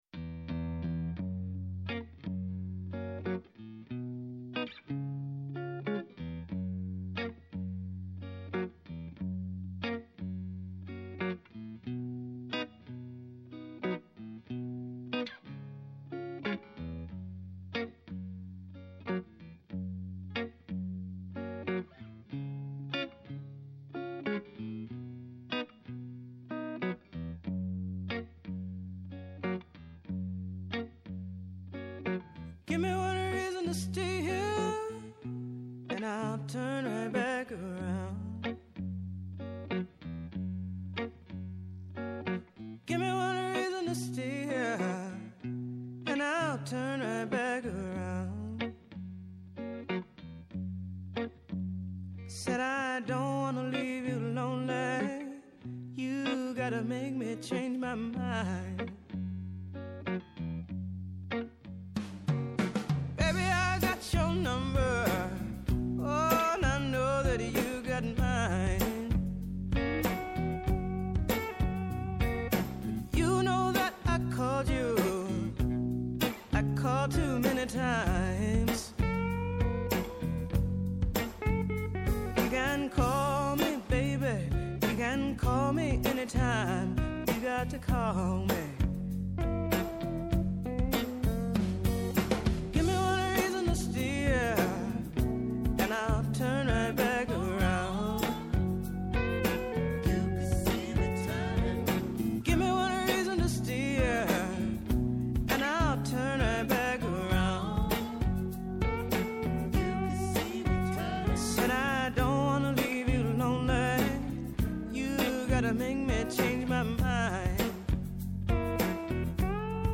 Ο απόηχος της εγχώριας και διεθνούς πολιτιστικής ειδησιογραφίας με στόχο την ενημέρωση, τη συμμετοχή, και τελικά την ακρόαση και διάδραση. ‘Ενα ραδιοφωνικό “βήμα” σε δημιουργούς που τολμούν, αναδεικνύουν την δουλειά τους, προτείνουν και αποτρέπουν.